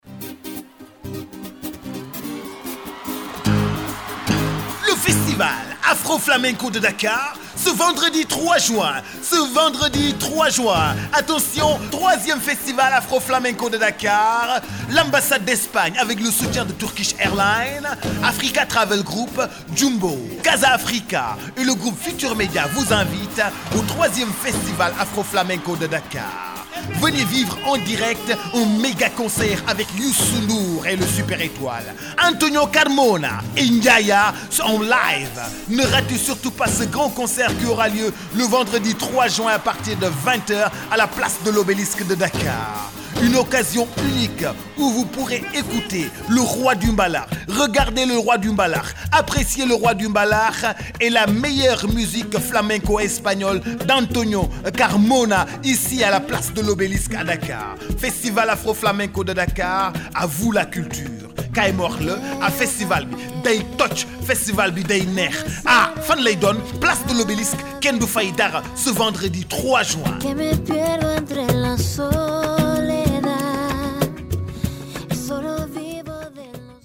06_Festival_Afroflamenco_Spot.MP3